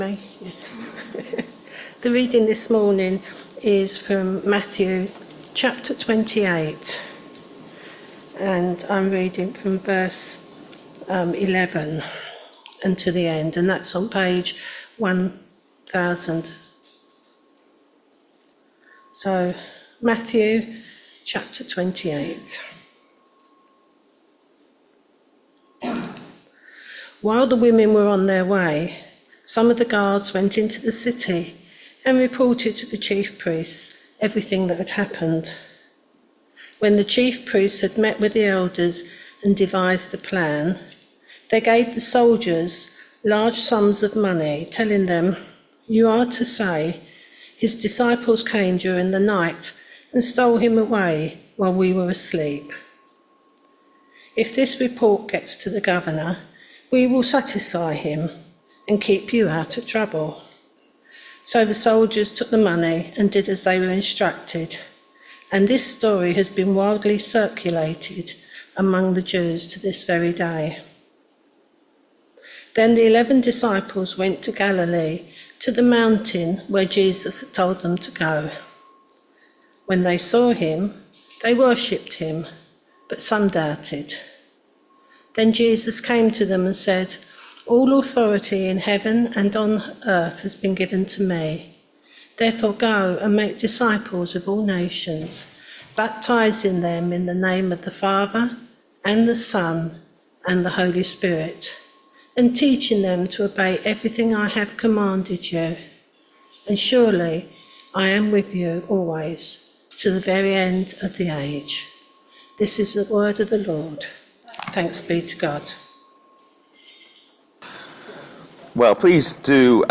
Matthew 28vv11-20 Service Type: Sunday Morning Service Topics